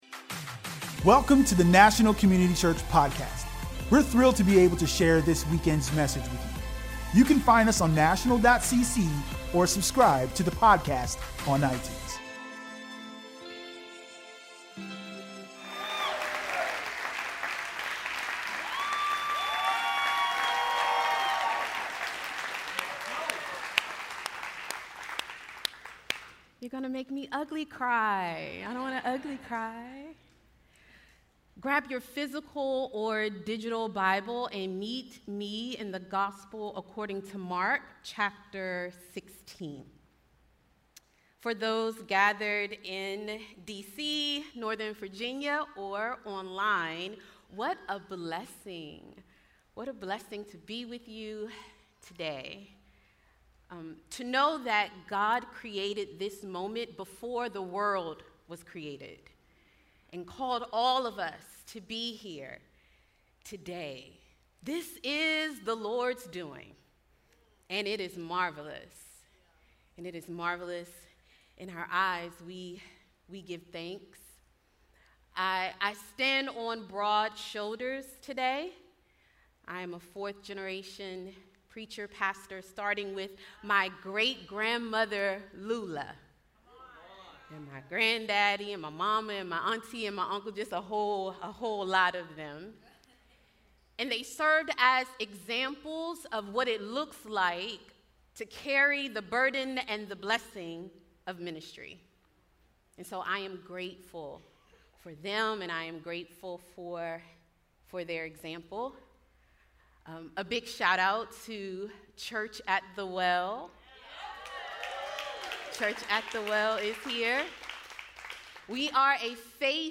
Audio messages from National Community Church in Washington, DC.